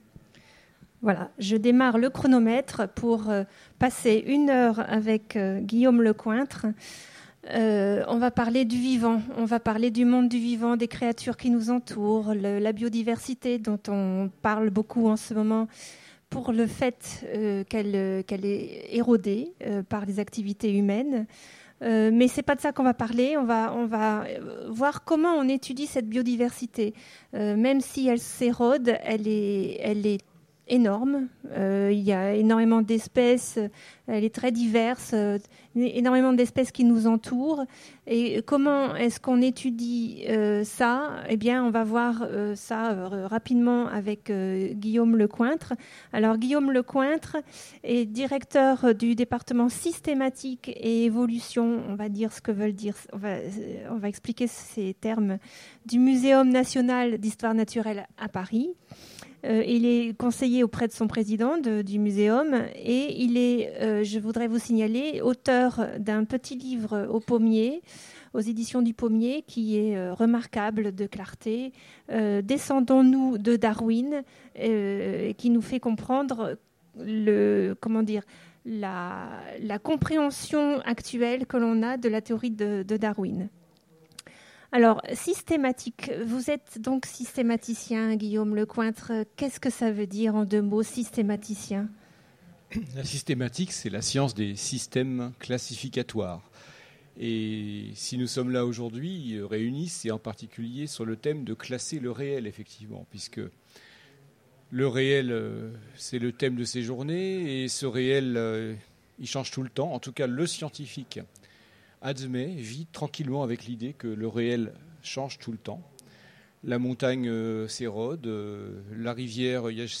- le 31/10/2017 Partager Commenter Utopiales 2015 : L’homme qui classe le réel biologique Télécharger le MP3 à lire aussi Guillaume Lecointre Genres / Mots-clés Rencontre avec un auteur Conférence Partager cet article